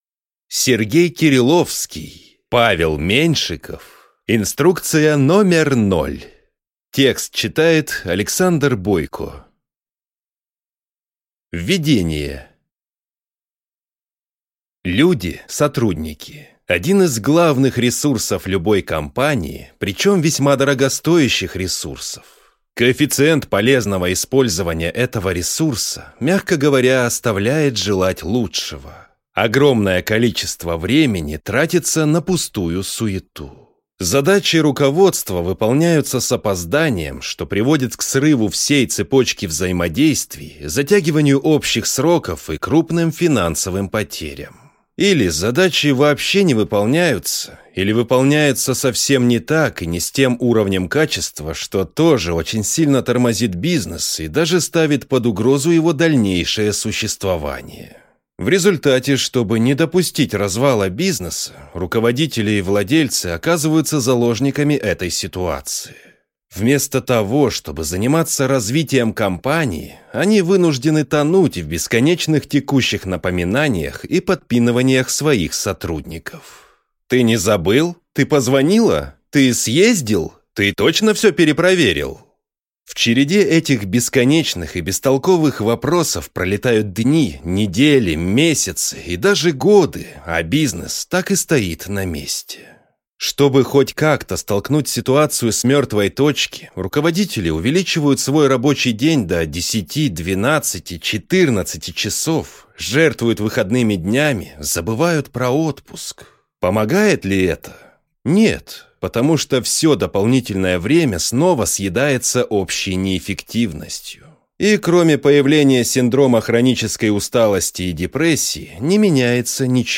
Аудиокнига Инструкция номер ноль: Дал задачу и забыл. Взял задачу – дальше сам | Библиотека аудиокниг